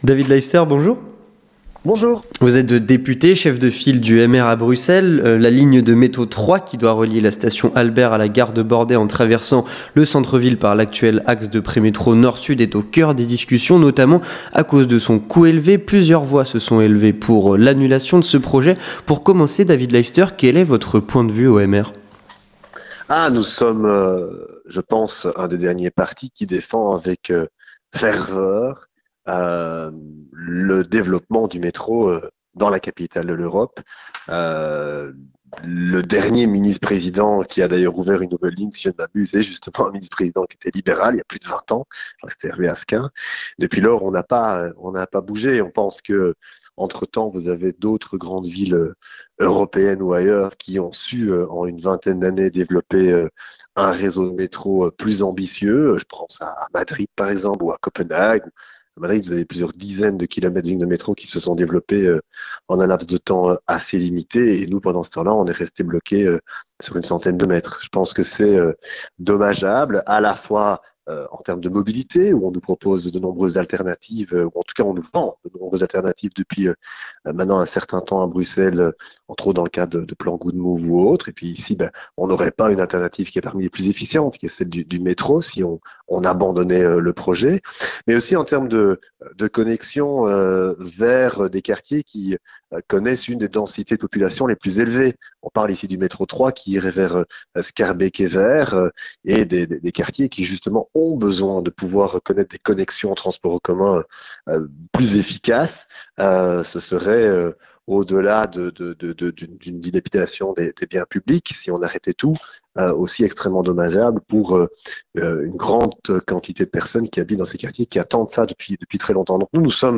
Entretien du 18h - Les travaux de la ligne de métro 3 bientôt à l'arrêt ?